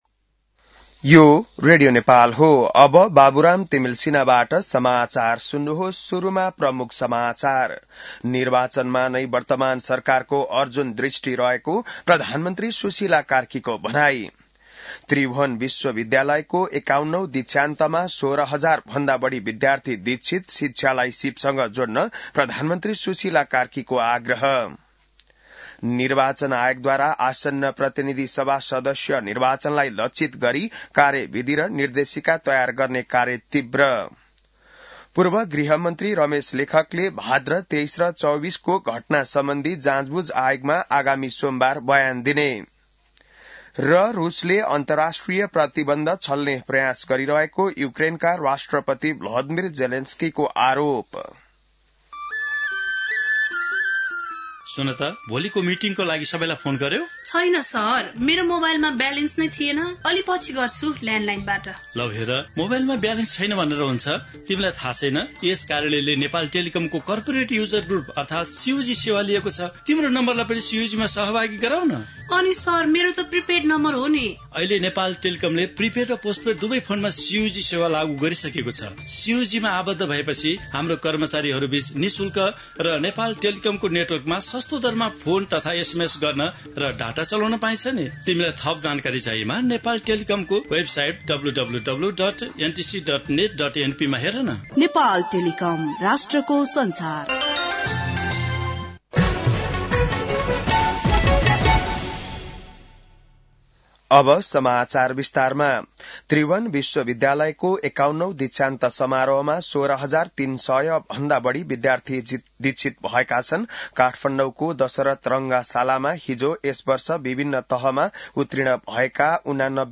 बिहान ७ बजेको नेपाली समाचार : ११ पुष , २०८२